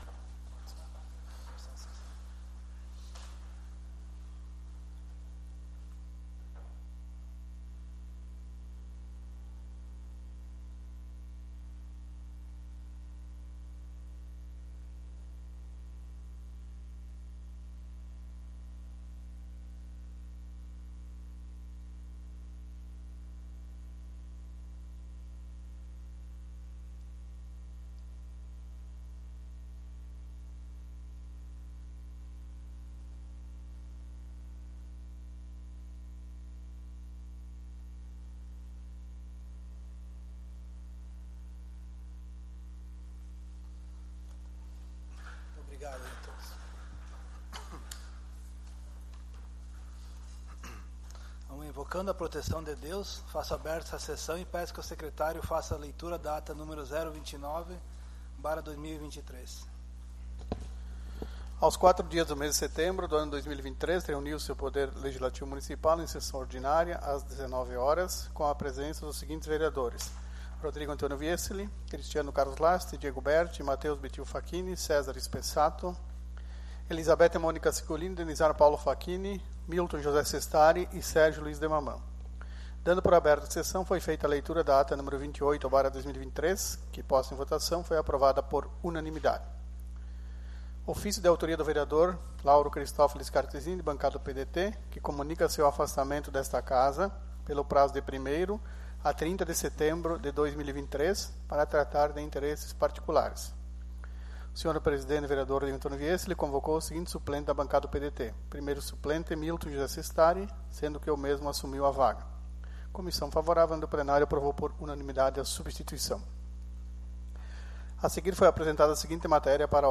Sessão Ordinária - 11/09/2023